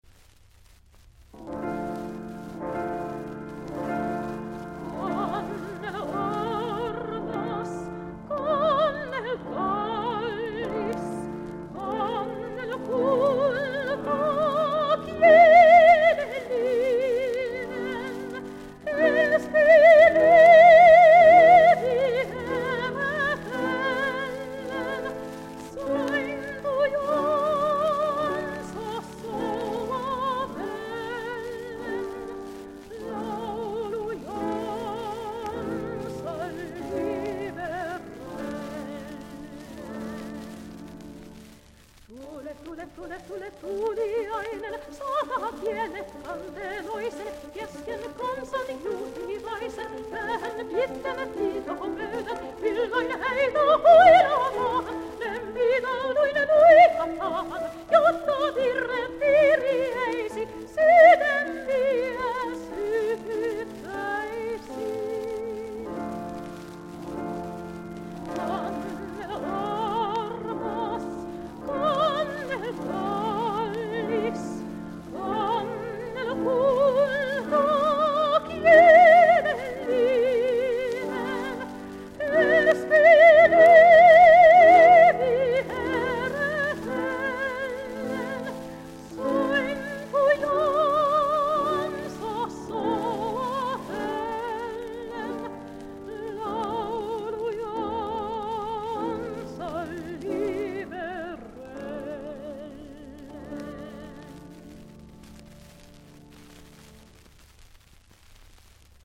Lyyris-dramaattinen sopraano